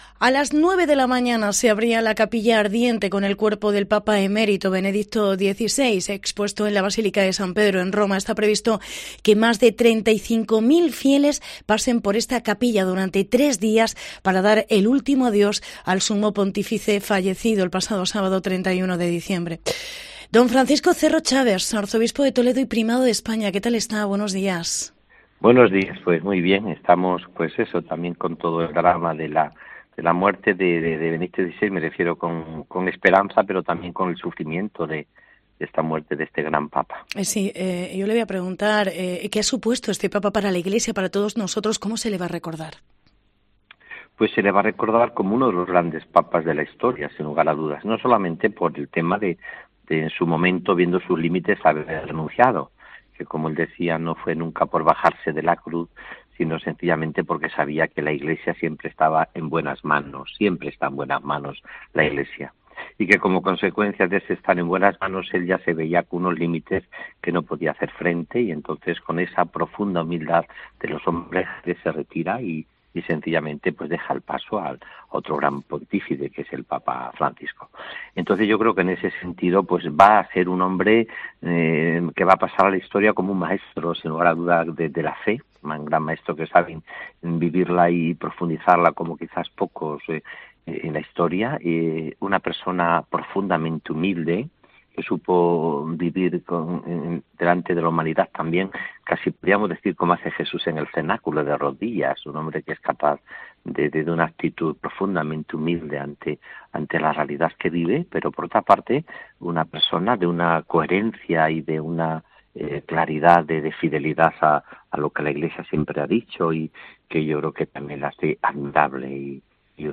Entrevista al arzobispo de Toledo Francisco Cerro Chaves por la muerte de Benedicto XVI